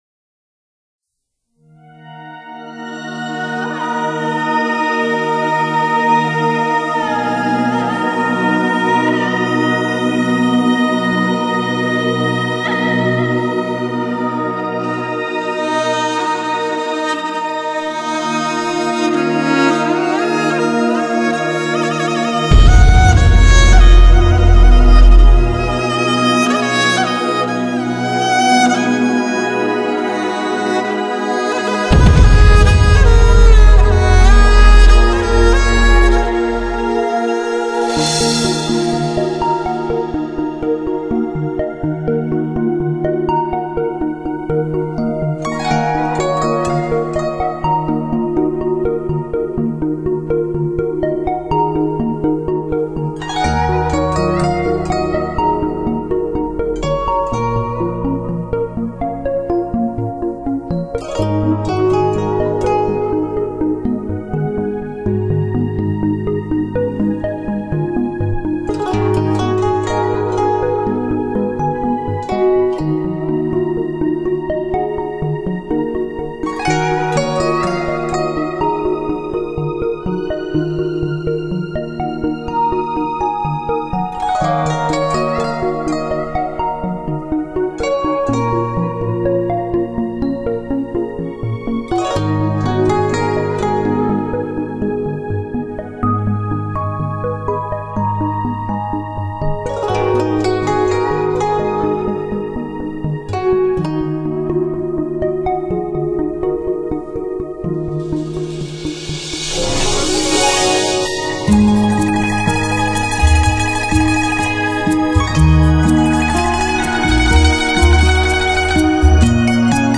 录音：八度音场录音棚
风情“万”种的异域格调，淑雅幽深的“纯”净品味，“狂”野神秘的轮指演绎！